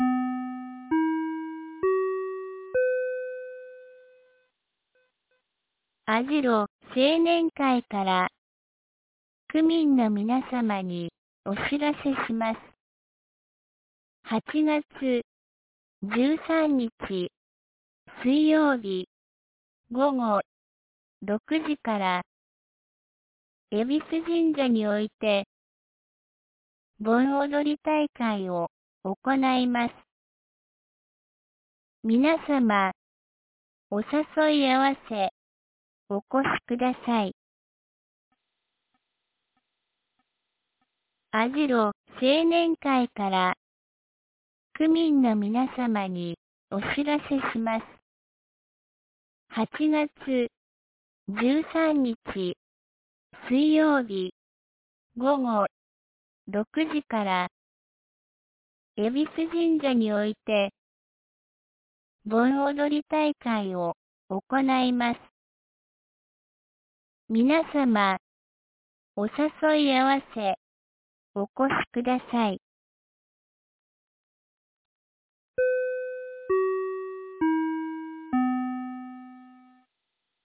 2025年08月10日 12時21分に、由良町から網代地区へ放送がありました。